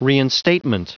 Prononciation du mot reinstatement en anglais (fichier audio)
Prononciation du mot : reinstatement